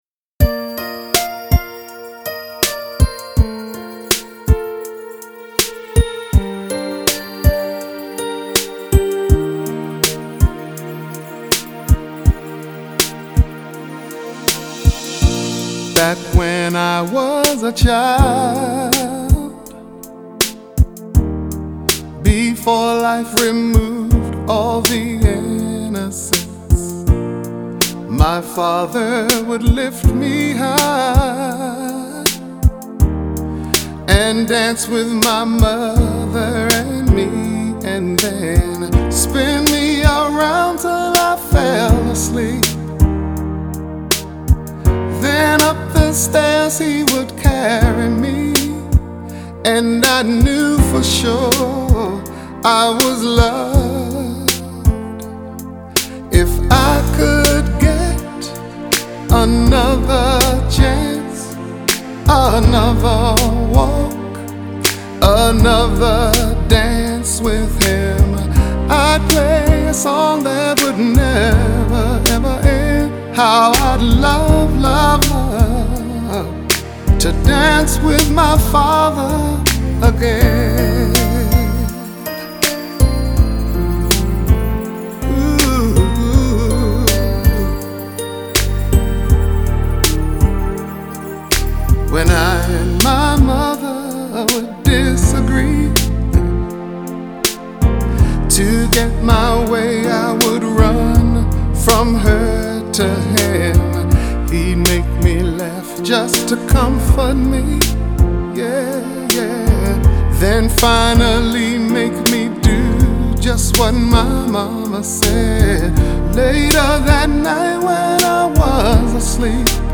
他是80年代黑人音乐三巨头之一，也是最伟大的黑人男歌手之一。
很简单的旋律，很深情的表达了对父亲的怀念吧，呵呵！